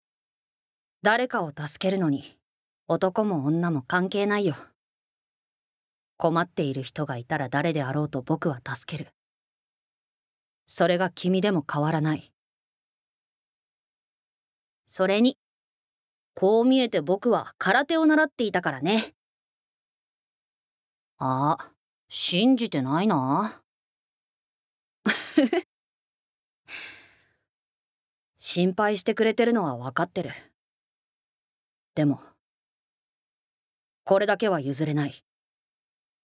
Voice Sample
ボイスサンプル
セリフ２